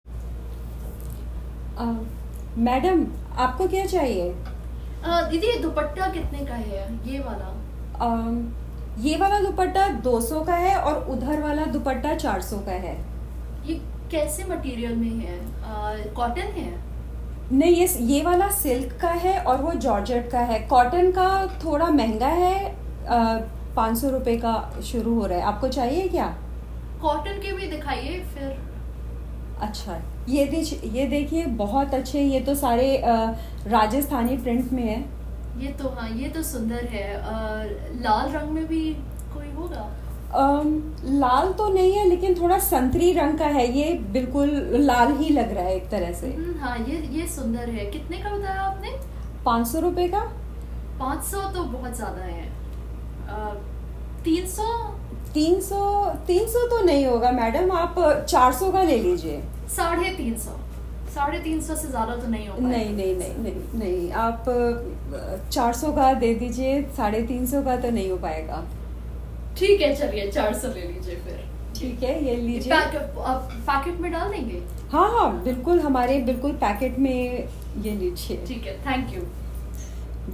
Role-play- Bargaining for a Dupatta: A Role-Play in an Indian Cloth Market
1. What do you think the woman is buying – क़मीज़, दुपट्टा, साड़ी?